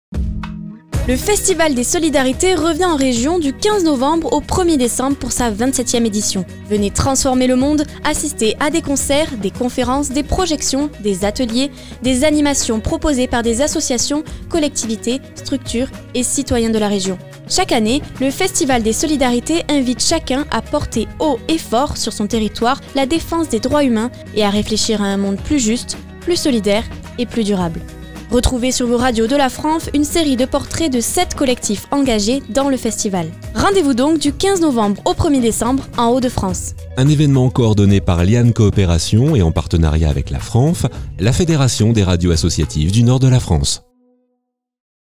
Voici le spot annonçant le festival des Solidarités en Région :
Spot-Festisol-REGION-2024.mp3